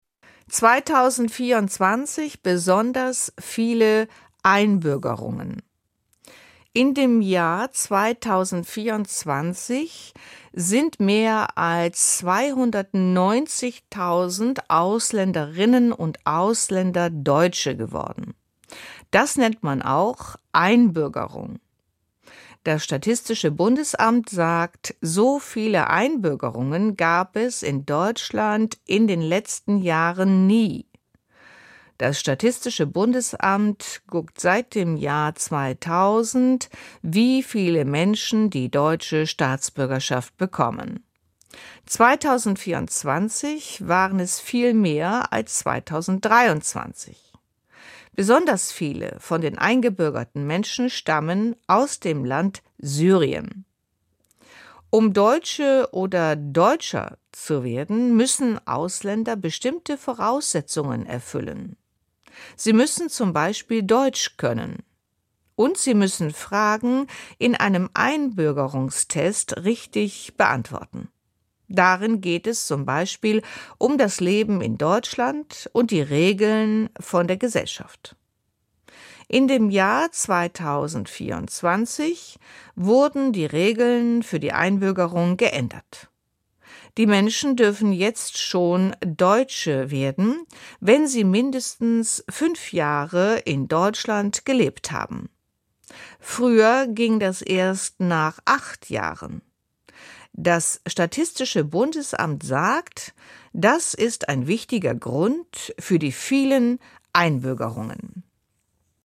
Unser Wochen-Rückblick zum Thema Vermischtes in einfacher Sprache. Von der Nachrichten-Redaktion vom Deutschland-Funk.